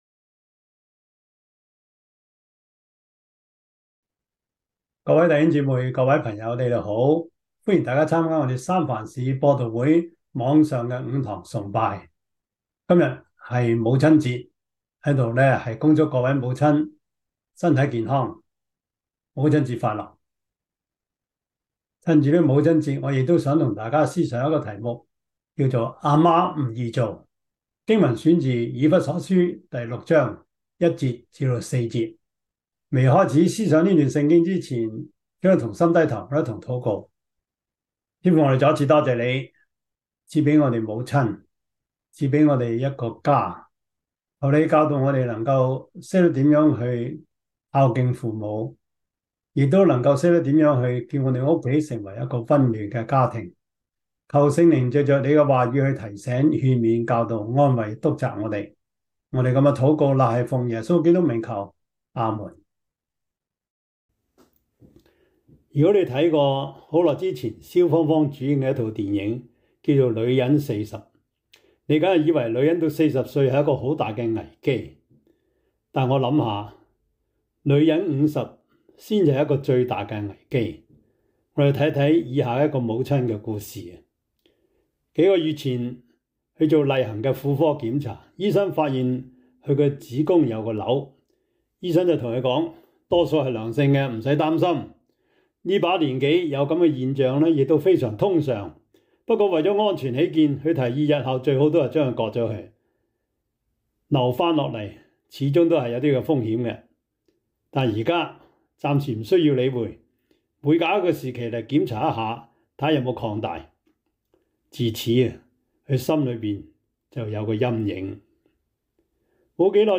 以弗所書 6:1-4 Service Type: 主日崇拜 以弗所書 6:1-4 Chinese Union Version
Topics: 主日證道 « 安身與至近的親屬 基礎神學 – 第二課 »